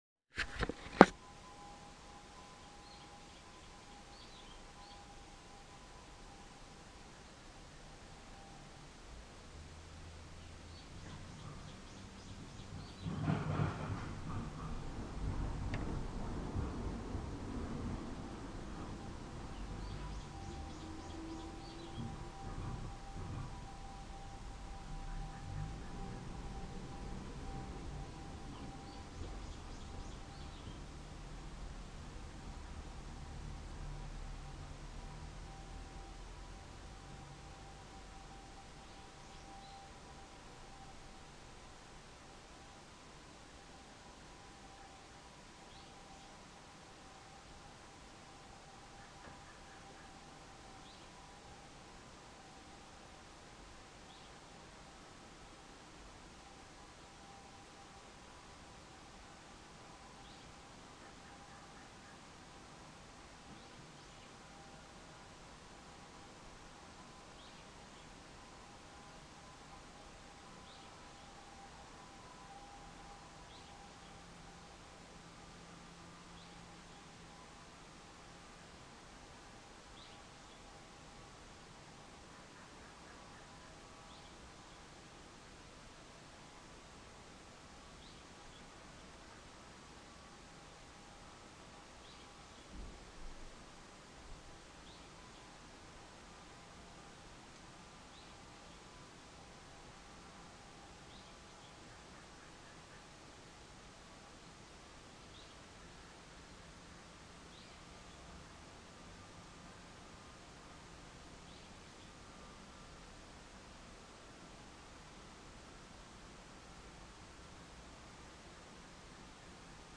このサイレンがなって車が走り出す場合、こんな感じの音を出して自宅前を走り抜けて行きます。
このガタンガタンと言う音は、溝に蓋をしてある石蓋の音です。
いかにも、車も空を飛ぶかのような感じで走り抜けて行きます。
siren_200511_0903
（カエルの鳴き声は、わたしが仕事をしていた時の流れでこの場所でも同じ工作を行っています。）
サイレンも本来であれば、もっと継続して鳴らしてもっと近づいてくると思っていましたが、録音しているのが分かったらしく途中で音が消えています。